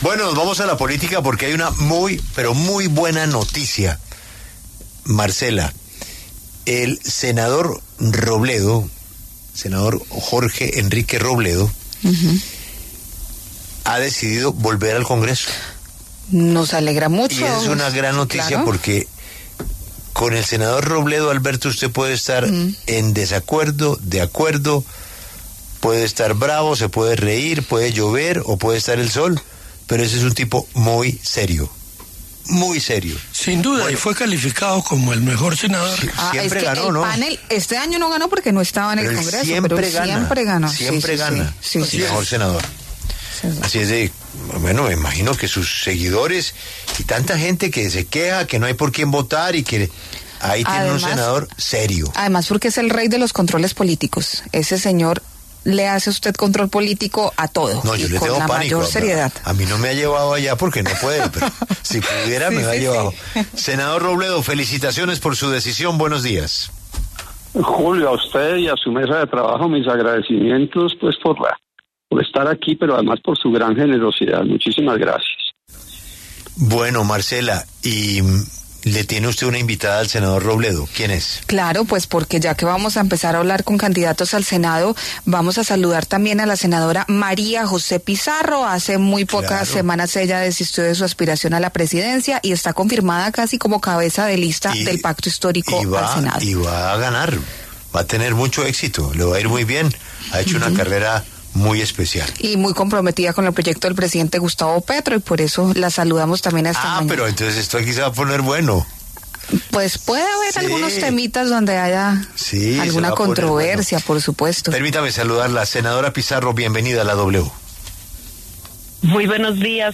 Debate Robledo - Pizarro: ¿Tiene Petro los votos para ser mayoría en Congreso y cambiar Constitución?